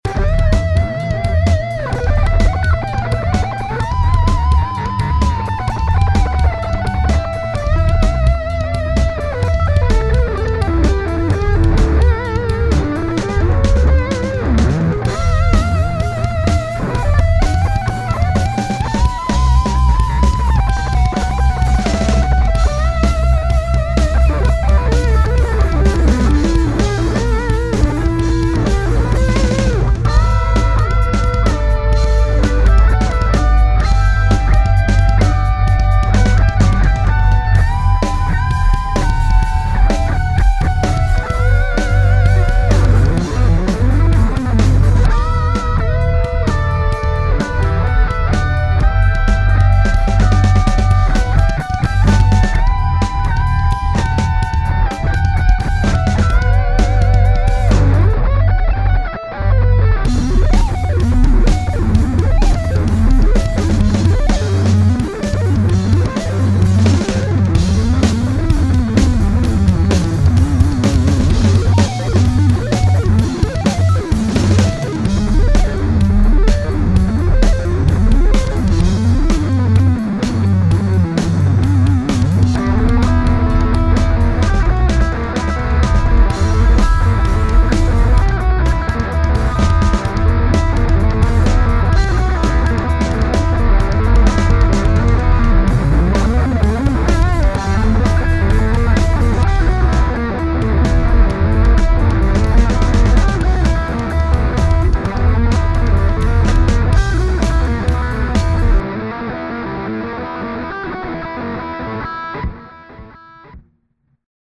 just another excerpt of something I made a few weeks back,the structure will change as I will record this again in the near future on my laptop,the bassline was once again recorded with an octaver.